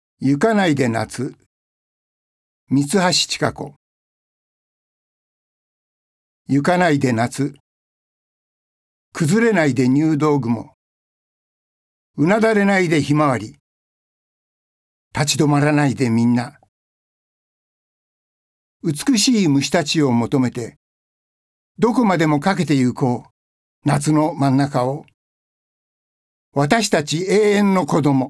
ボイスサンプル、その他